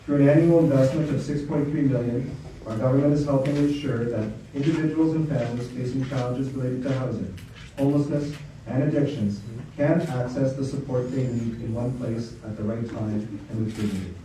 A grand opening ceremony for the Renfrew County Mesa HART Hub was held on Thursday, February 5th
Renfrew-Nipissing-Pembroke MPP Billy Denault spoke about the provincial government’s investments in expanding housing, mental health and addictions services.